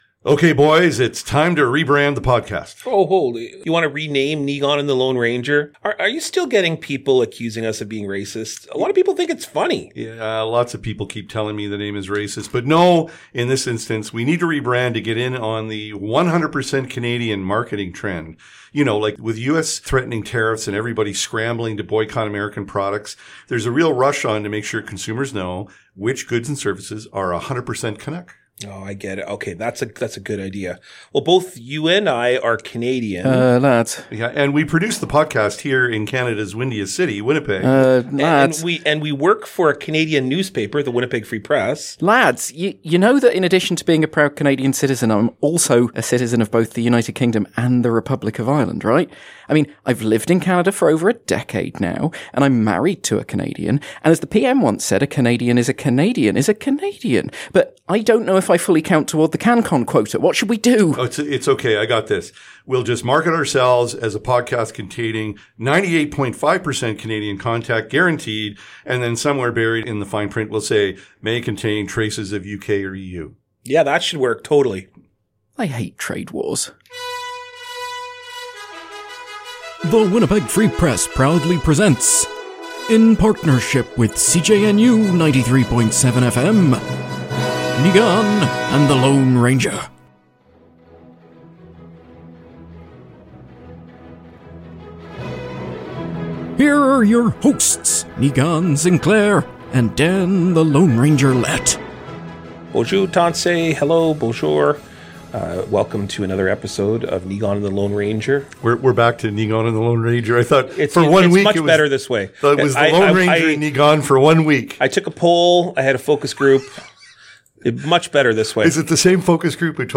Two newspaper columnists talk politics, culture and media from the geographical centre of Canada and ground zero in the debate over Indigenous reconciliation.